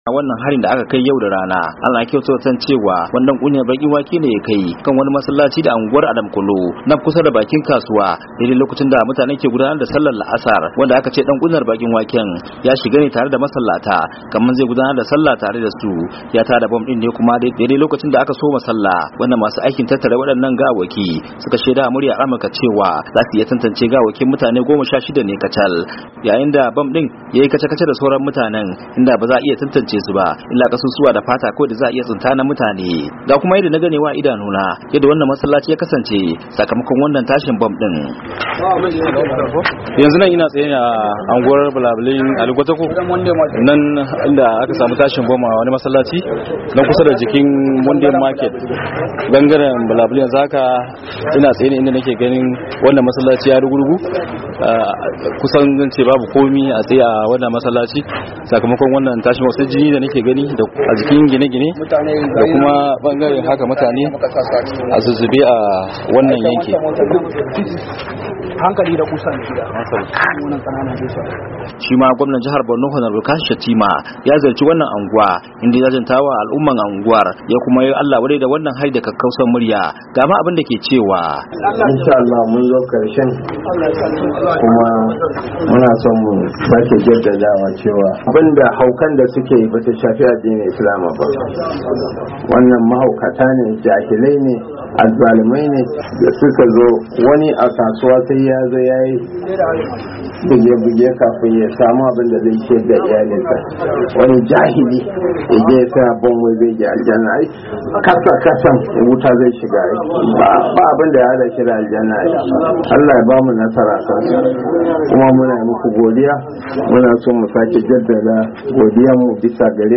Ga karin bayani a rahoton wakilin Muryar Amurka